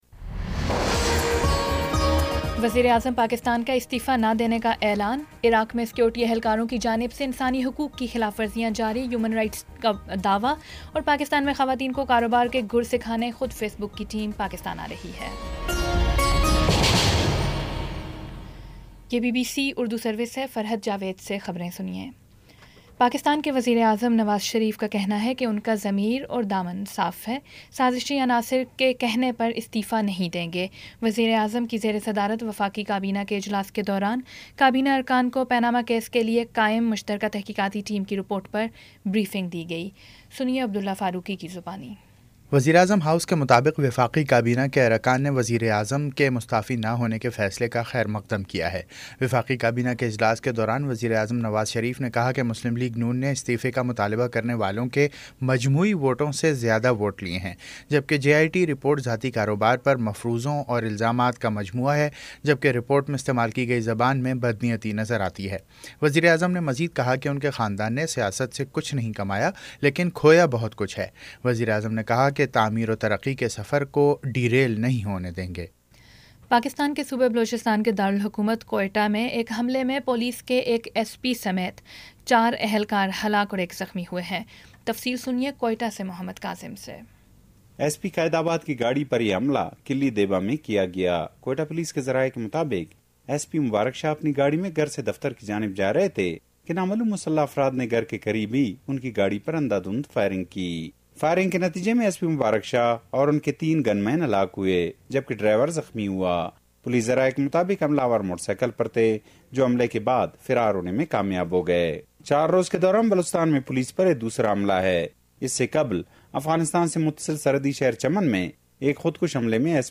جولائی 13 : شام چھ بجے کا نیوز بُلیٹن